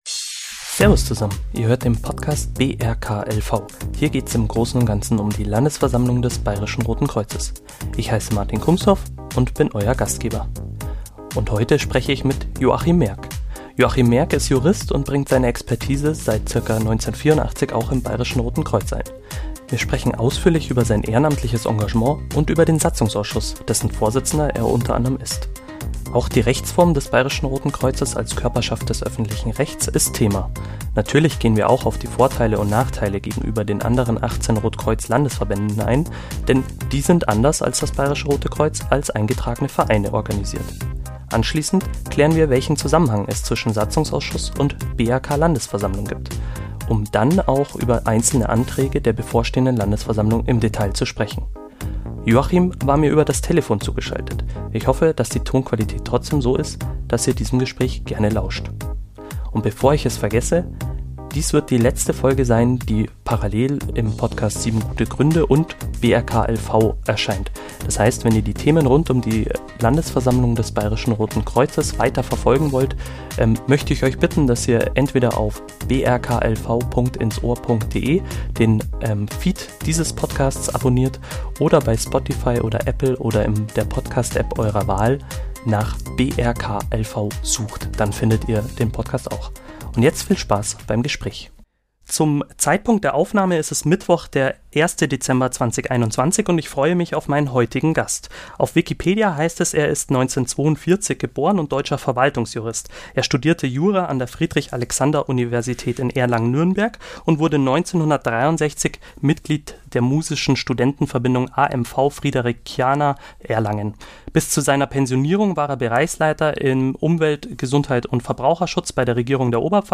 Ich hoffe, dass die Tonqualität trotzdem so ist, dass ihr diesem Gespräch gerne lauscht.